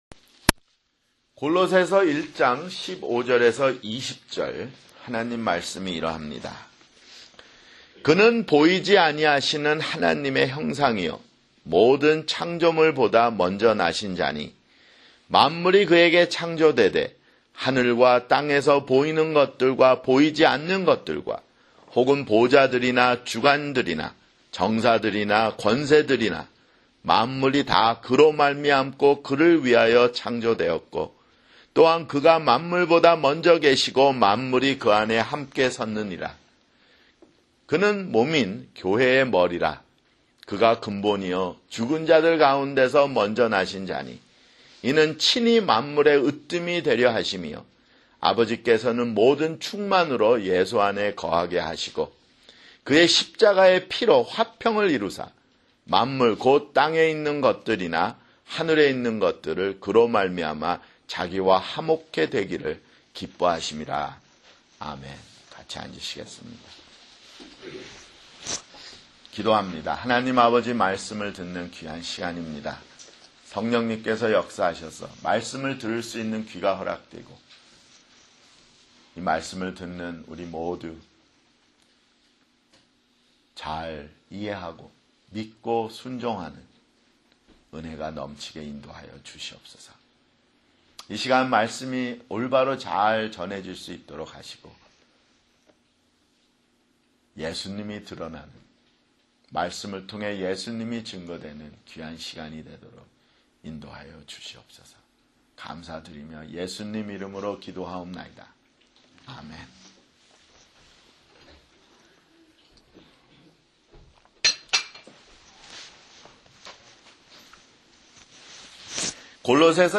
[주일설교] 골로새서 (26)